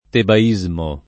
tebaismo [ teba &@ mo ]